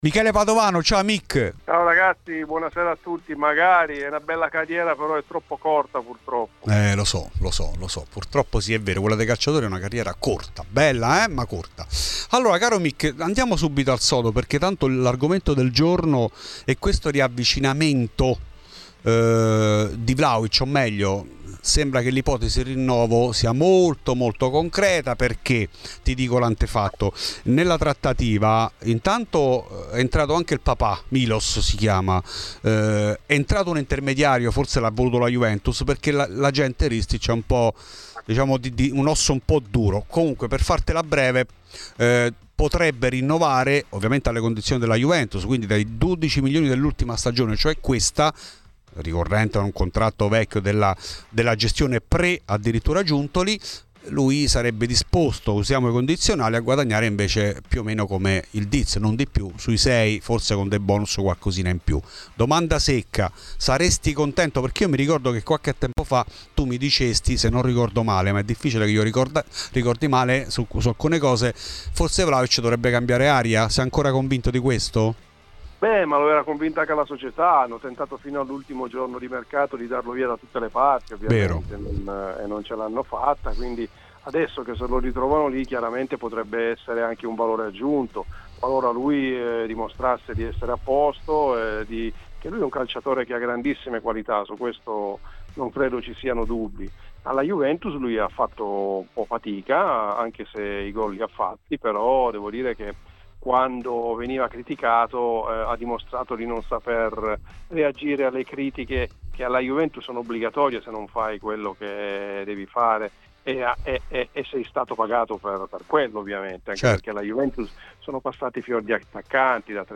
A Radio Bianconera, durante Fuori di Juve, è intervenuto l'ex attaccante Michele Padovano, che è partito dall'analisi del possibile rinnovo di Dusan Vlahovic: "Hanno provato a darlo via fino all'ultimo giorno di mercato, ora che è lì potrebbe essere anche un valore aggiunto.